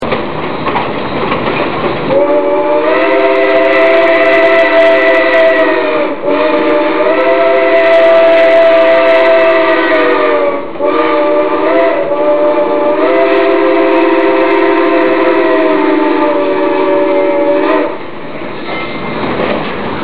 The conductor explained to me that they had just changes the whistle from a 6 pipe to a 5 pipe.